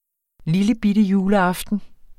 Udtale [ ˈliləˌbidəˌjuːləˈɑfdən ]